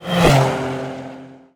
car6.wav